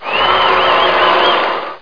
CHEER.mp3